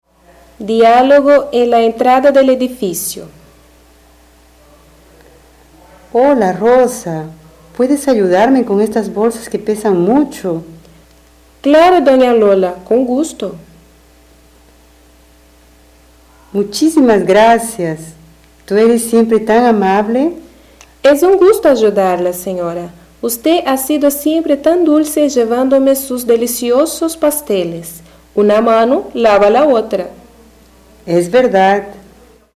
Description: Áudio do livro didático Língua Espanhola I, de 2008. Diálogo com expressões populares.